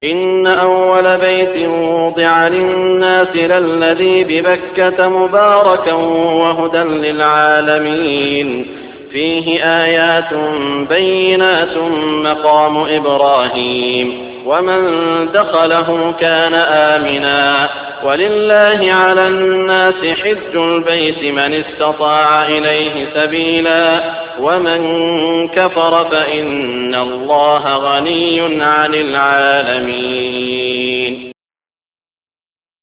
Recitation Holy Qur'an